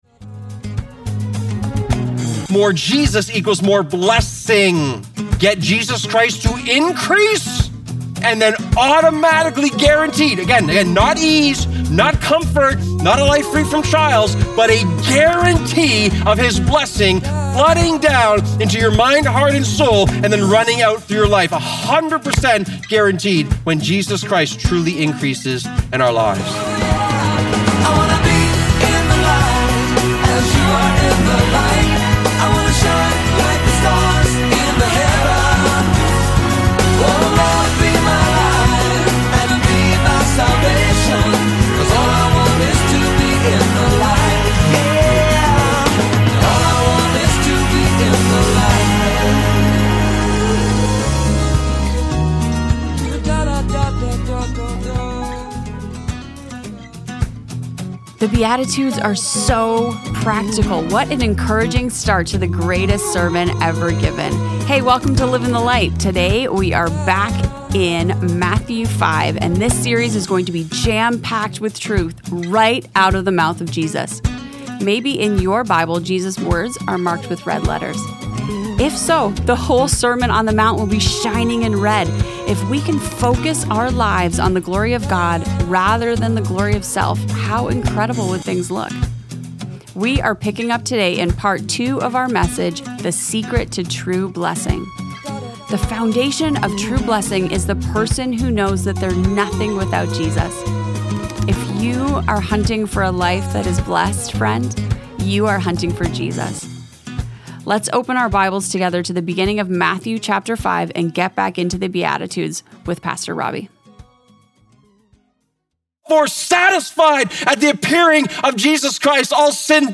In his sermon, Jesus begins with the Beatitudes, focusing on a person's character.